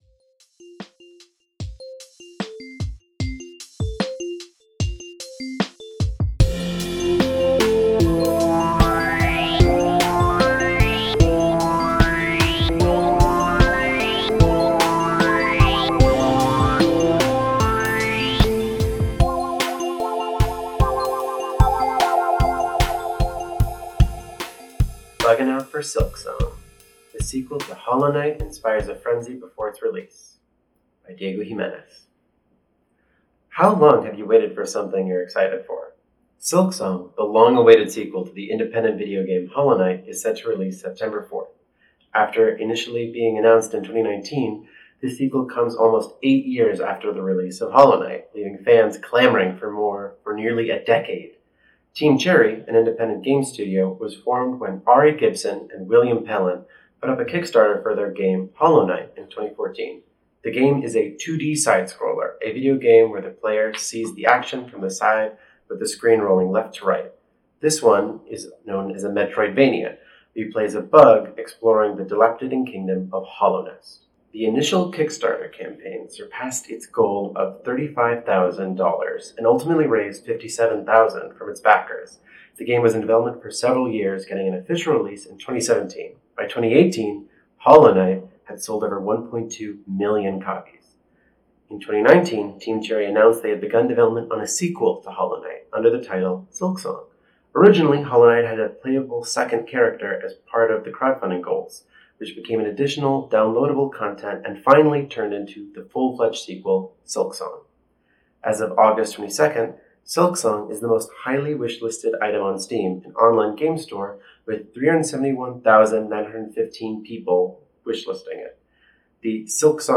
The text of this article was read aloud and recorded for your greater accessibility and viewing pleasure: